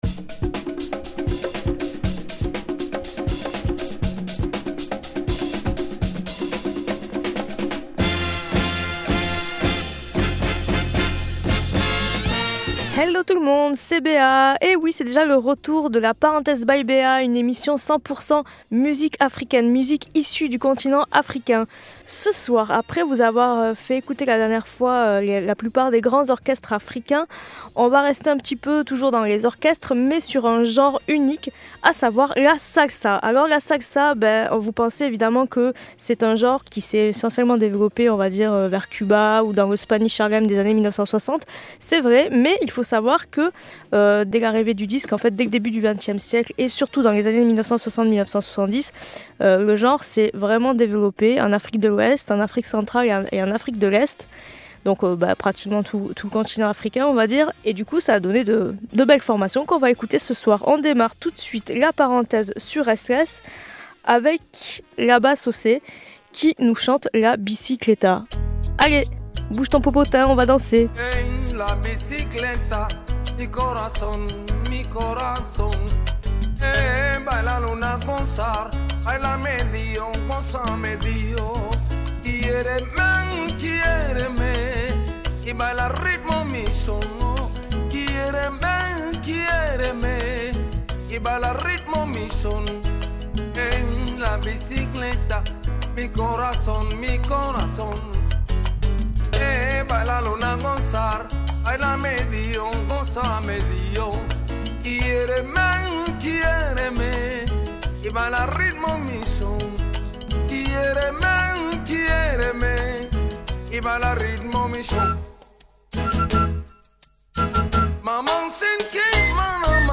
salsa africaine